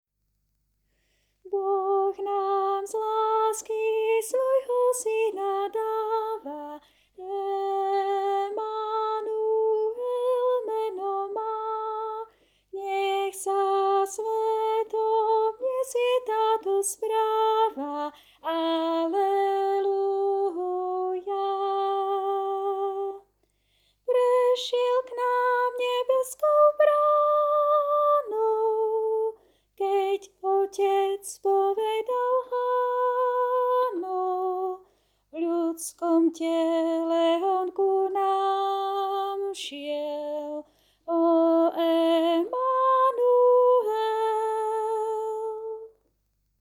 Boh_nam_z_lasky-Sopran.mp3